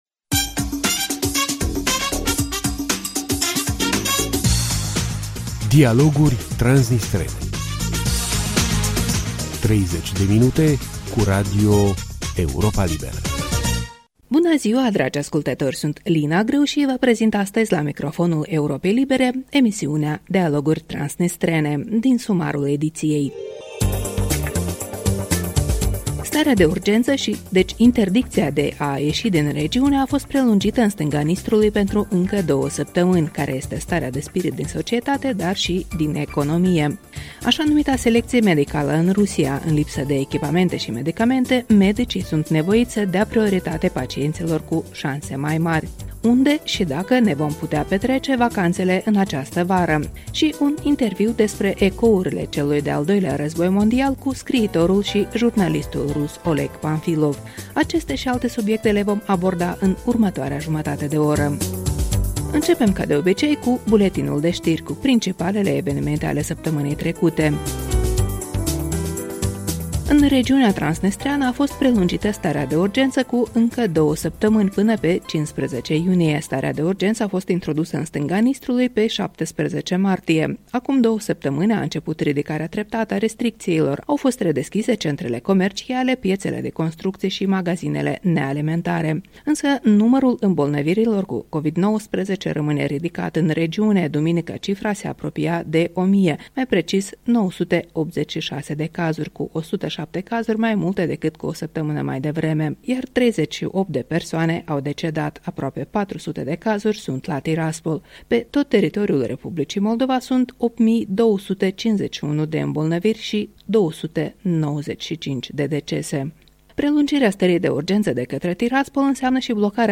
O emisiune moderată